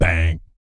BS BANG 03.wav